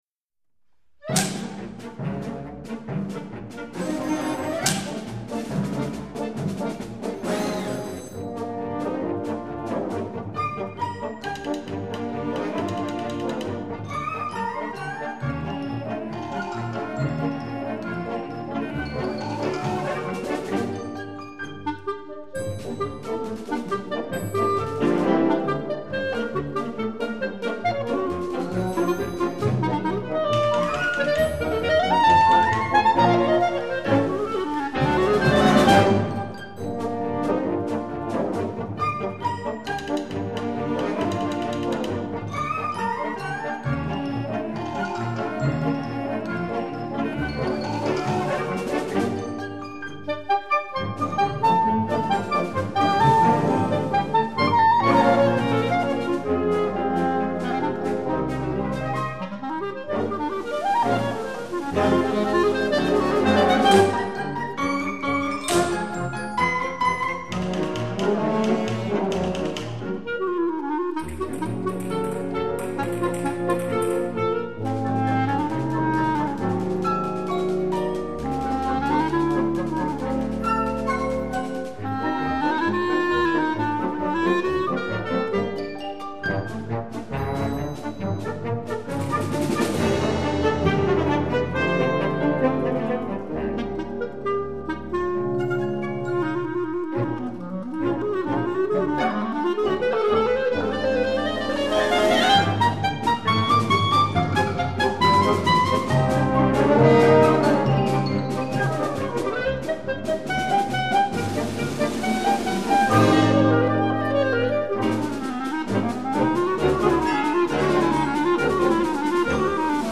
Clarinette Solo et Orchestre d'harmonie